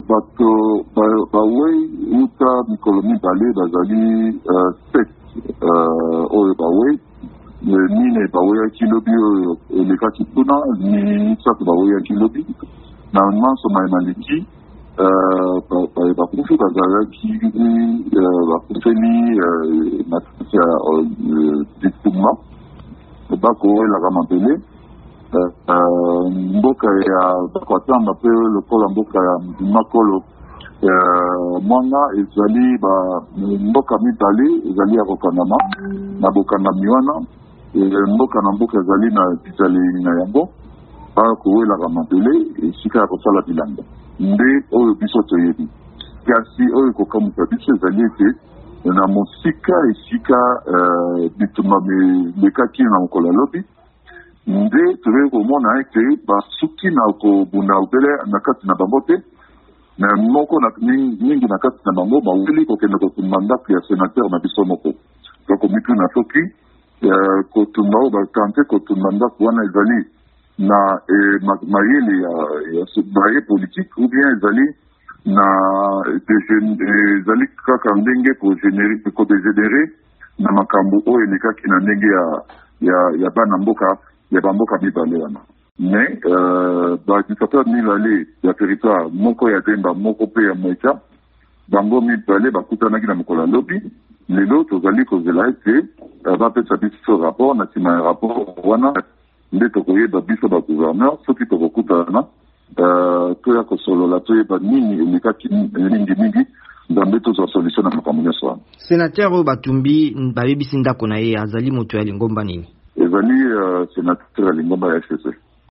VOA Linigala ebangaki mokambi ya etuka ya Kasaï, Dieudonné Pieme Tutokot.